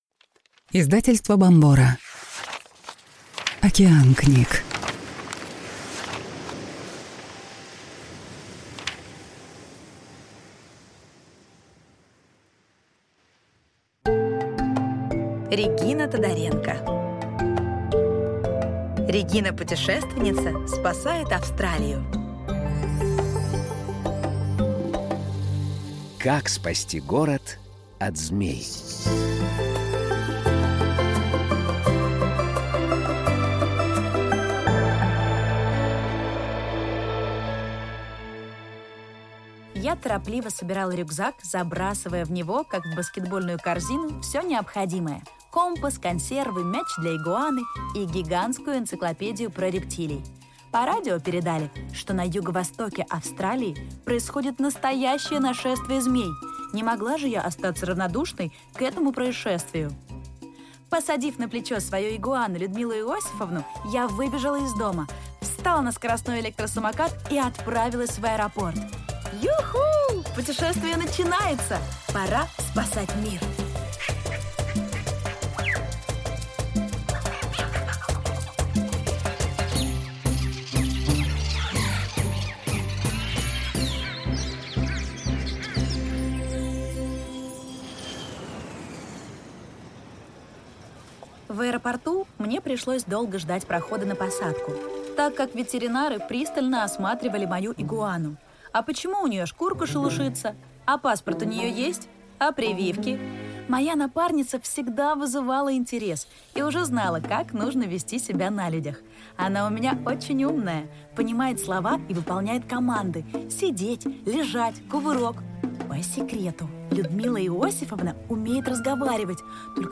Аудиокнига Регина-путешественница спасает Австралию | Библиотека аудиокниг